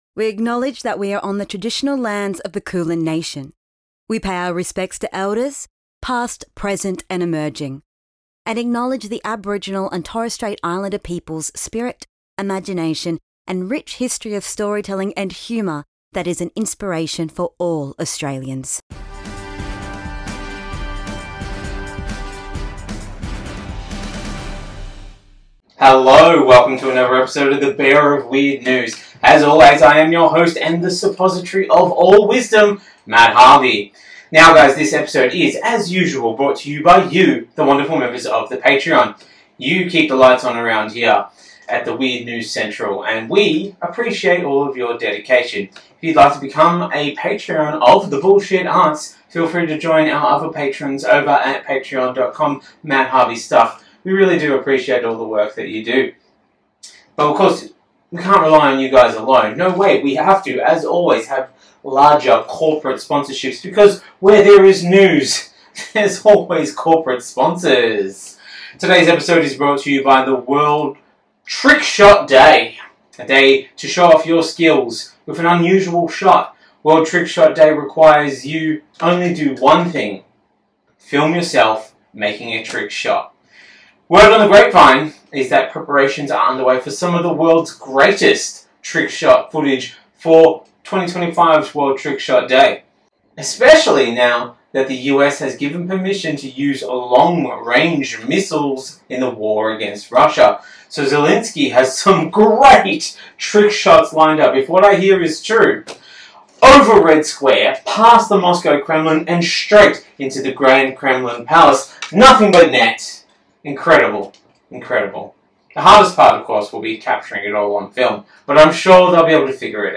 Australian news round-up